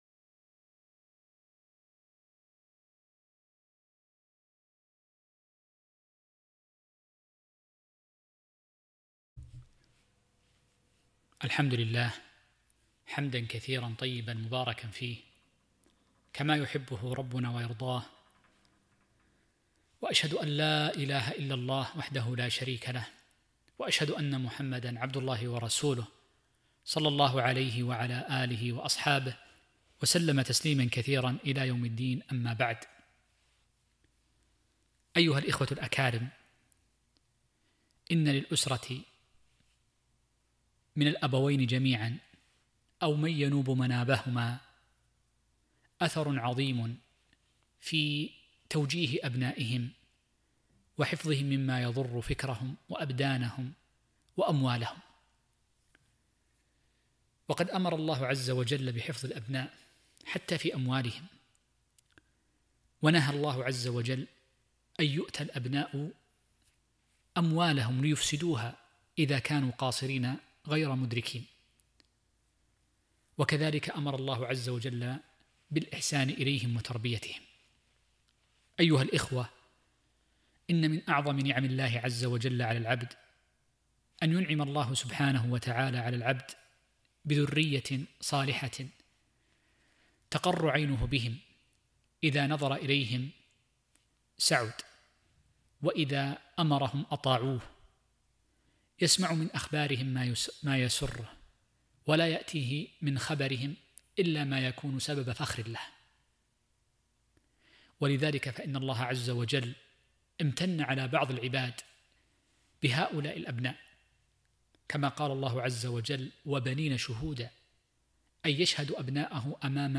محاضرة - دور الأسرة في تعزيز الأمن الفكري